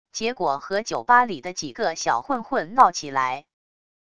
结果和酒吧里的几个小混混闹起来wav音频生成系统WAV Audio Player